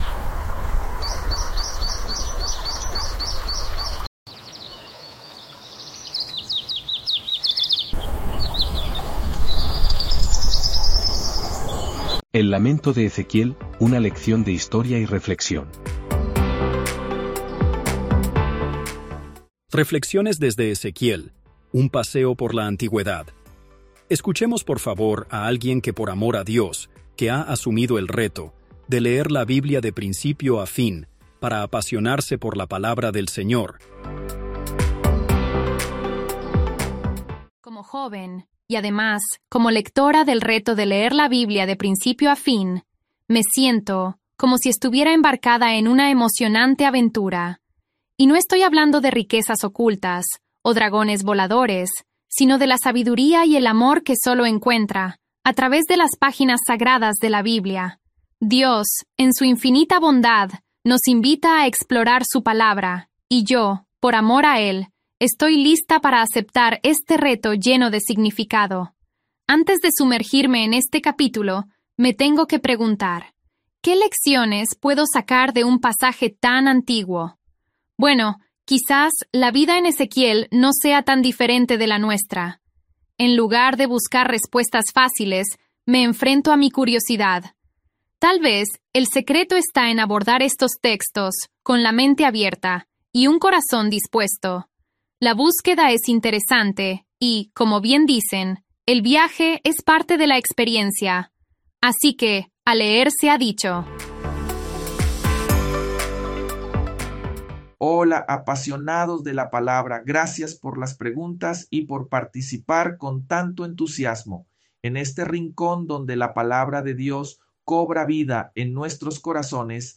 Ezequiel-19-Audio-con-musica-1.mp3